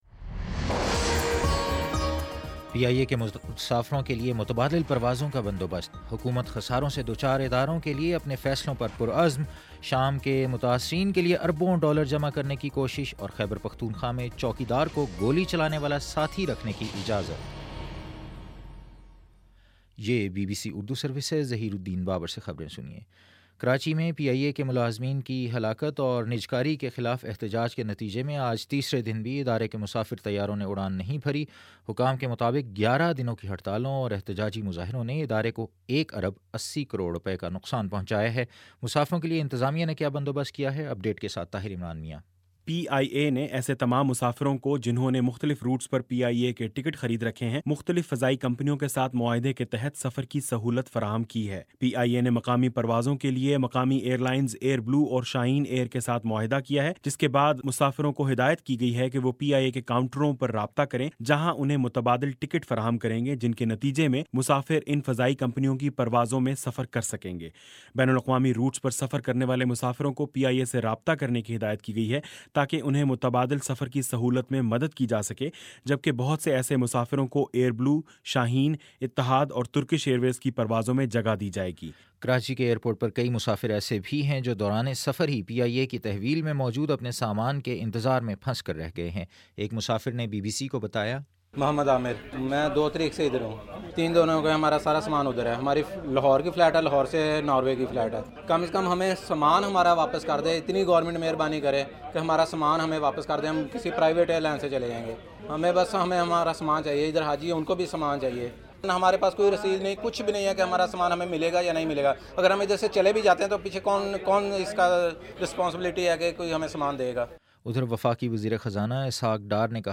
فروری 04 : شام پانچ بجے کا نیوز بُلیٹن